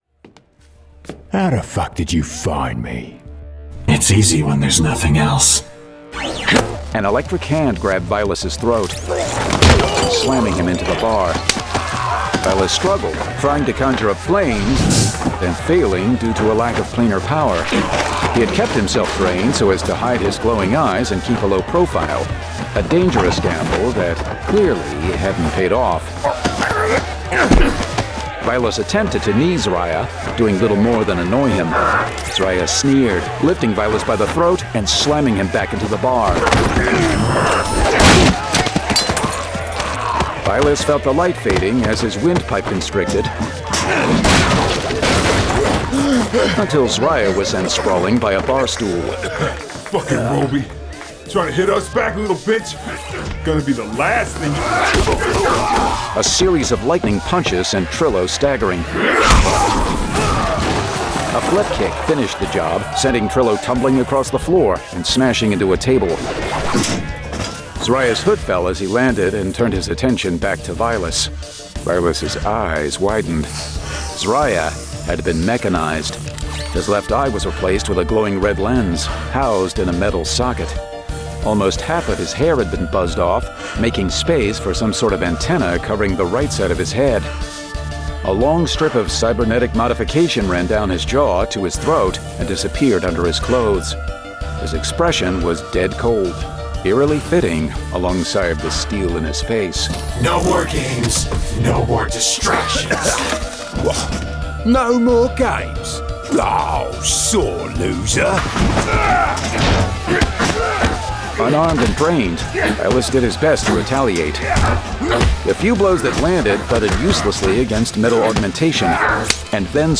Playing Gods 2: Adaptation [Dramatized Adaptation]